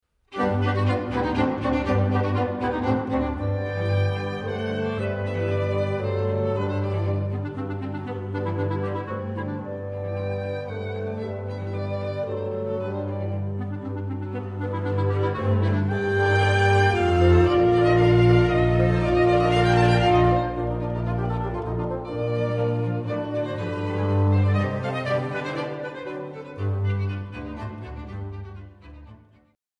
Fassung für Kammerensemble
für Septett